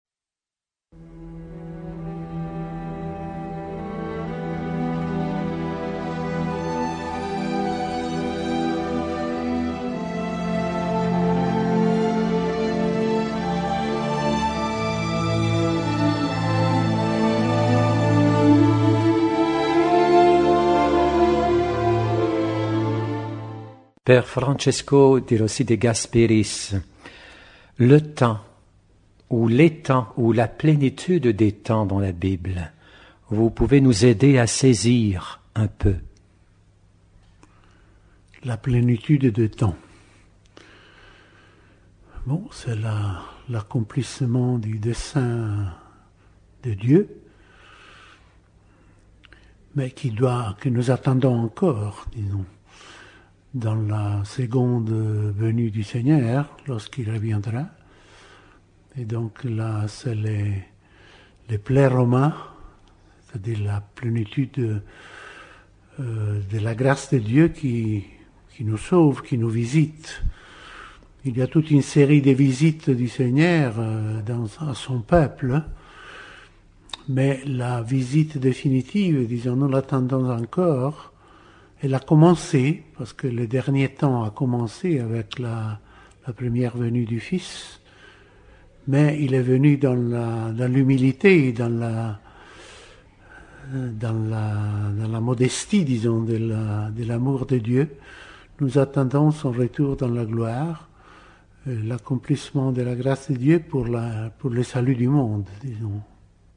Trois grandes méditations spirituelles et pratiques pour l'expression de sa foi de chaque jour par un jésuite